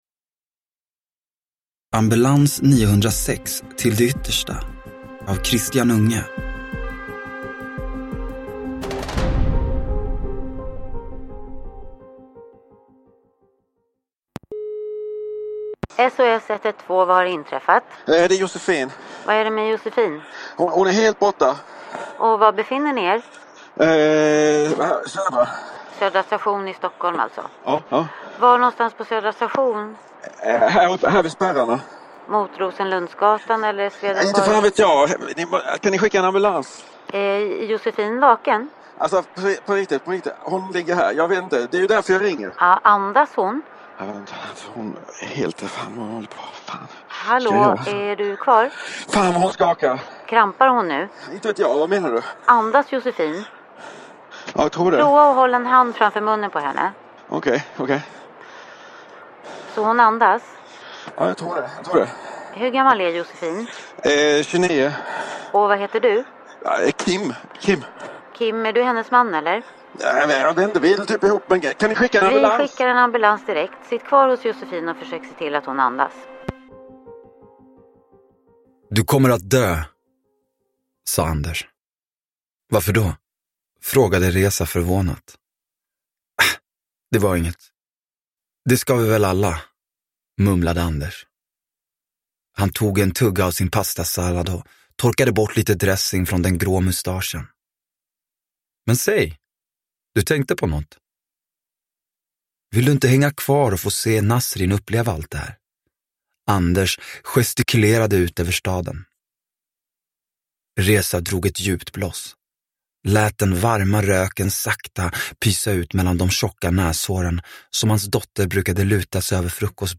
Till det yttersta – Ljudbok – Laddas ner